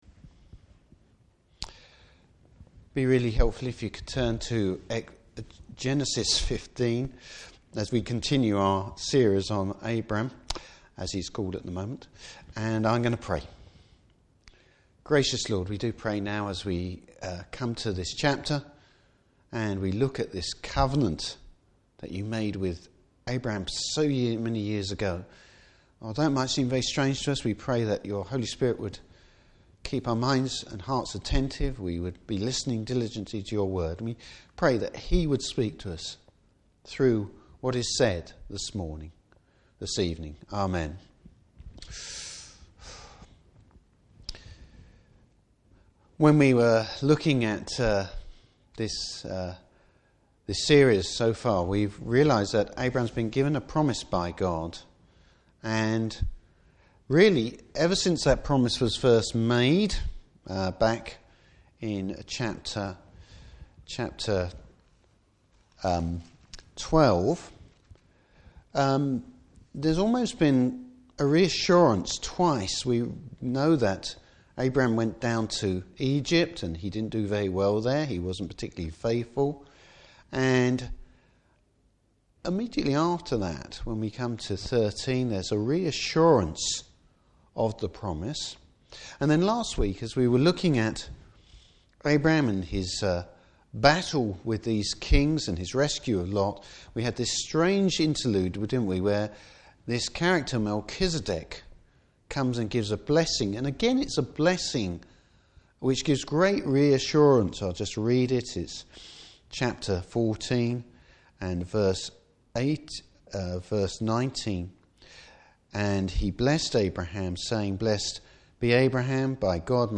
Service Type: Evening Service How grace and faith combine.